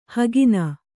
♪ hagina